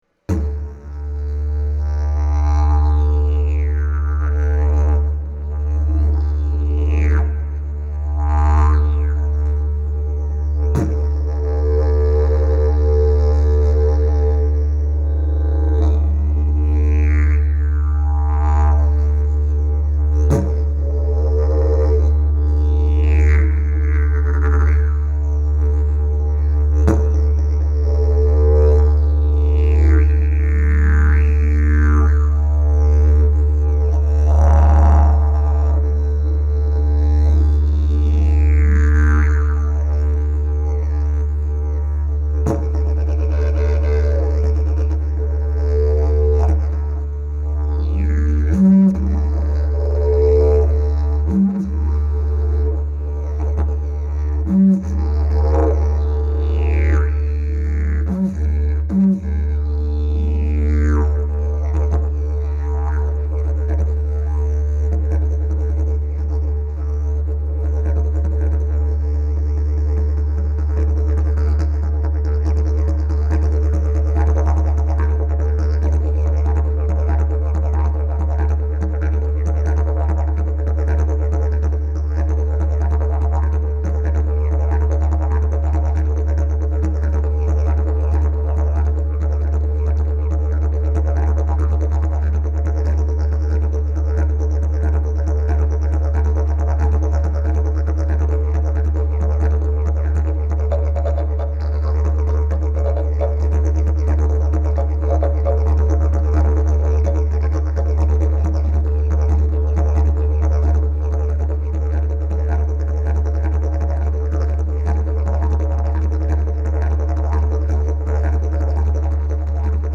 Key: D Length: 53.5" Bell: 3.4" Mouthpiece: Cherry, Bloodwood Back pressure :Very strong Weight: 4.2 lbs Skill level: Any
Didgeridoo #676 Key: D